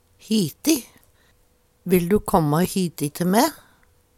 hiti - Numedalsmål (en-US)